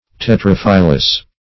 Search Result for " tetraphyllous" : The Collaborative International Dictionary of English v.0.48: Tetraphyllous \Te*traph"yl*lous\, a. [Tetra- + Gr.
tetraphyllous.mp3